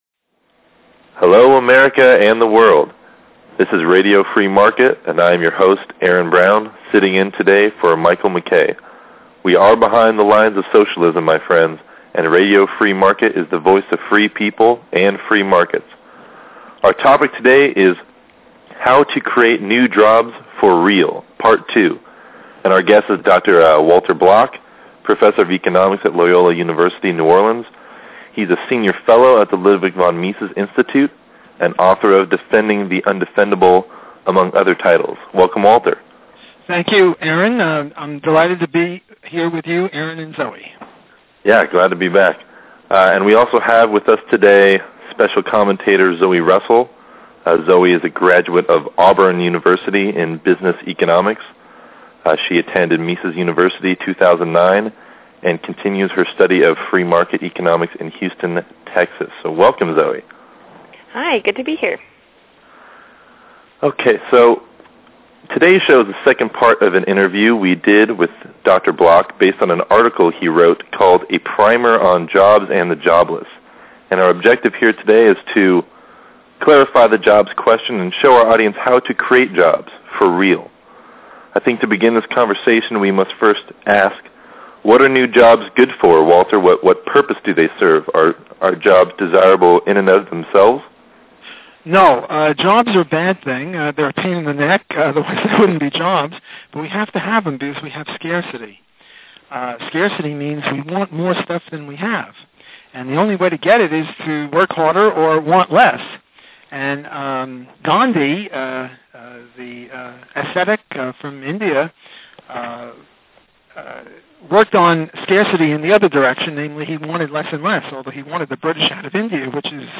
We will discuss these important issues in our second interview with Walter Block on Jobs and Employment .